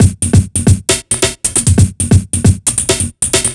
Rawness Break 135.wav